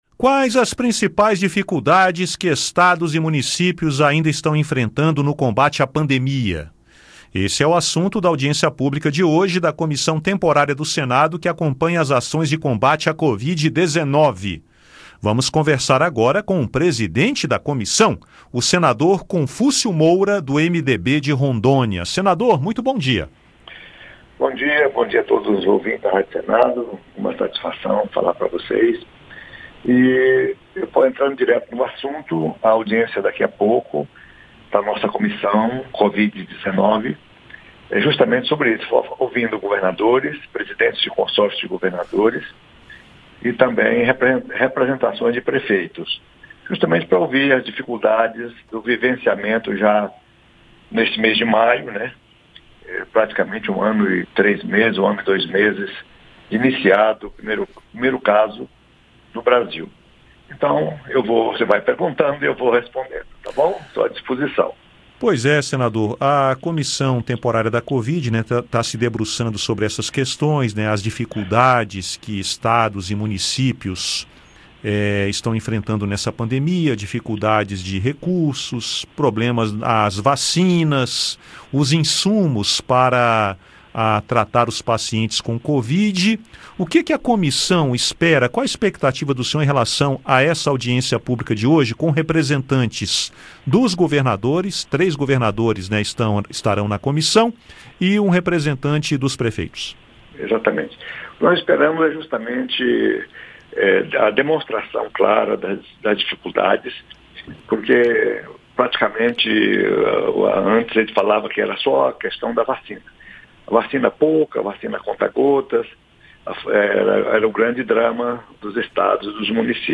O presidente da comissão, senador Confúcio Moura (MDB-RO), conversou com a Rádio Senado sobre as perspectivas e ações dos estados e municípios. Ele fala também sobre a participação da comunidade na audiência pública. Confira a entrevista.